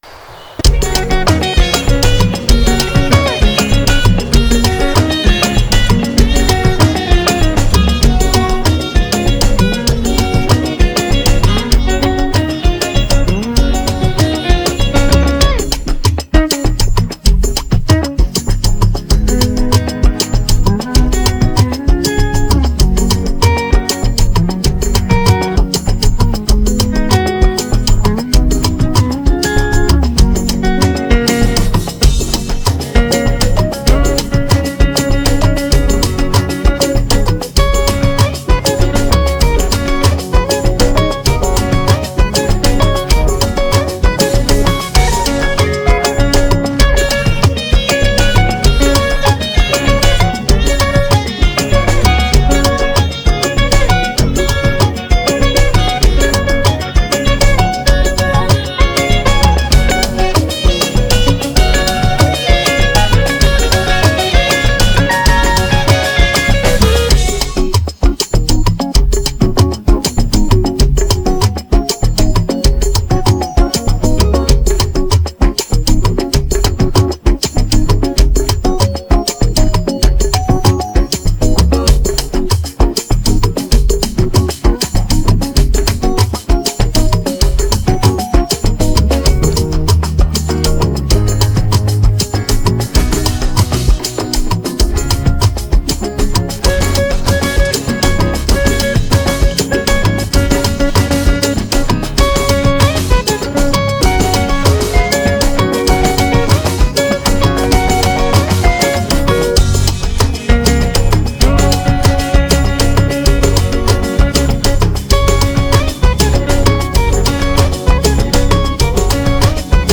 Afro pop Afrobeats